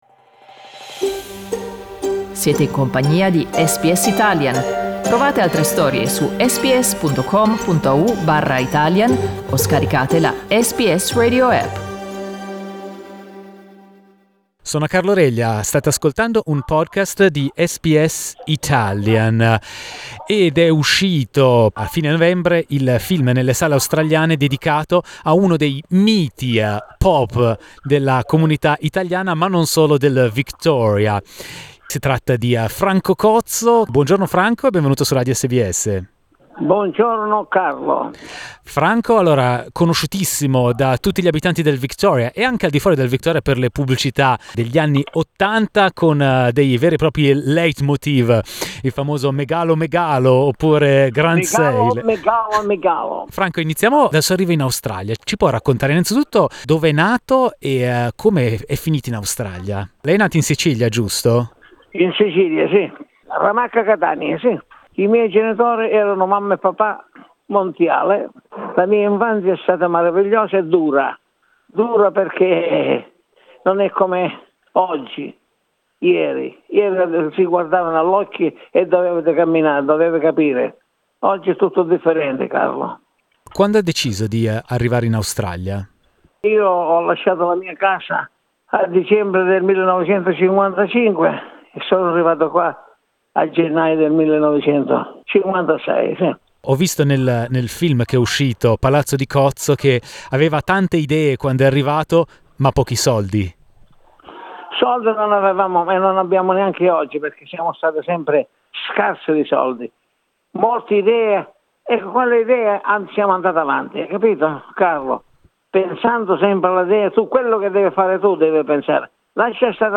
Franco Cozzo racconta la sua storia a SBS Italian.